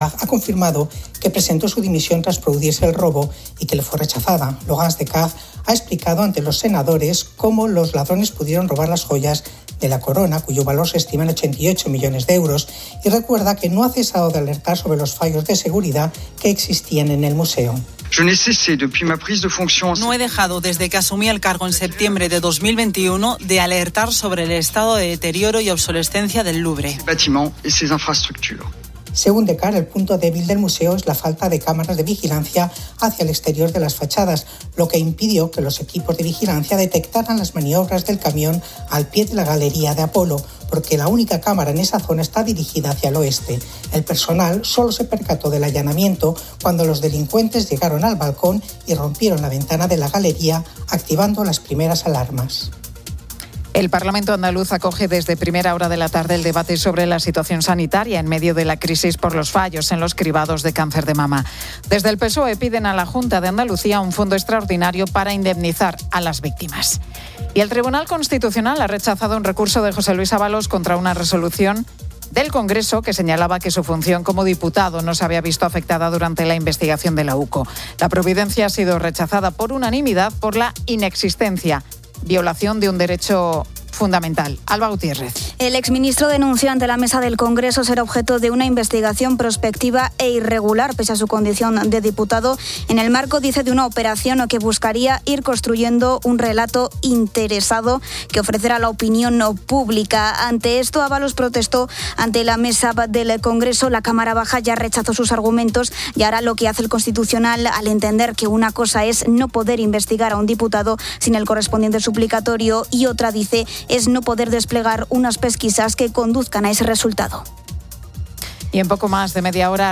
Los oyentes de COPE en diferentes zonas de España comentan la dificultad de vestirse debido a los cambios de temperatura y la preocupación por la falta de lluvia. Finalmente, se destaca la historia de la expedición Balmis, la primera misión sanitaria internacional que llevó la vacuna de la viruela a diferentes partes del mundo, un relato de ciencia, humanidad y futuro.